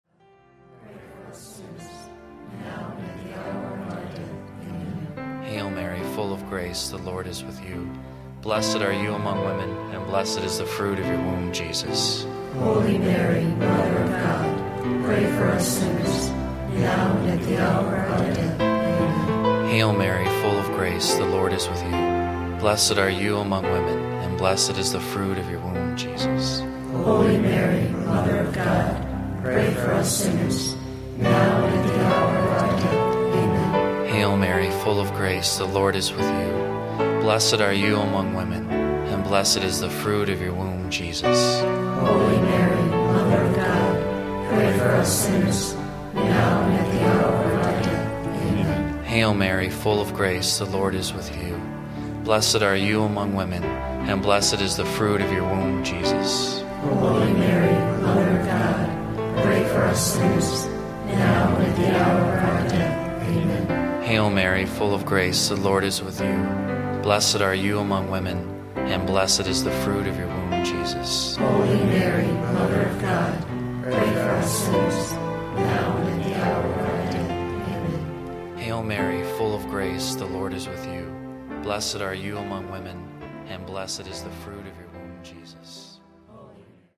• Recorded at the National Shrine of the Divine Mercy in Stockbridge, MA
• Recited version and the Chaplet of Divine Mercy